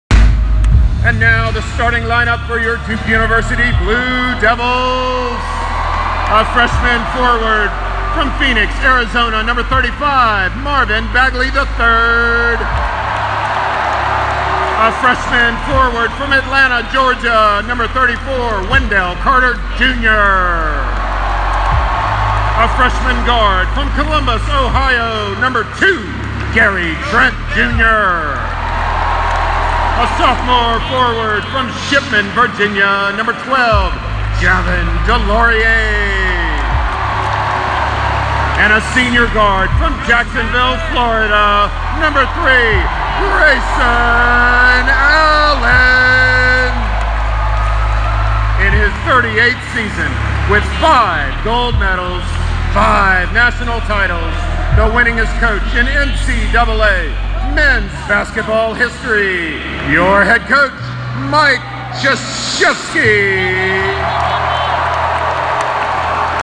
Mens BB starters Duke 110417 (m4a)